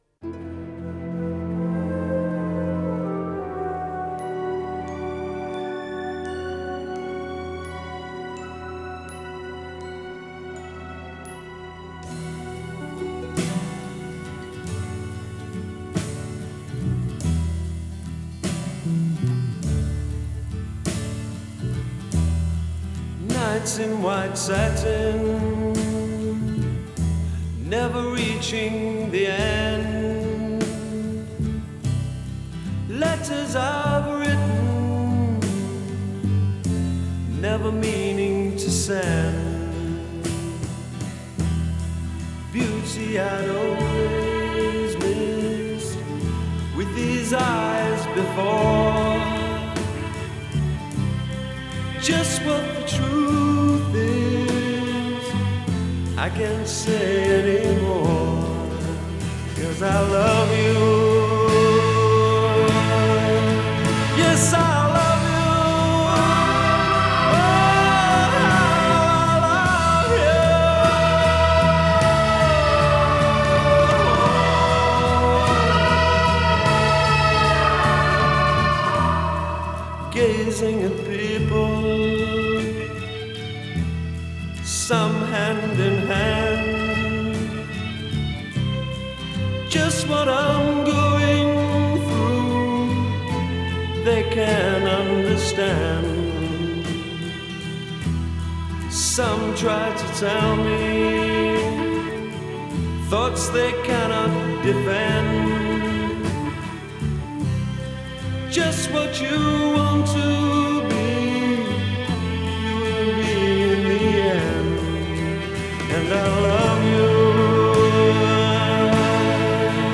Progressive rock
The symphonic parts give it a light an airy feeling.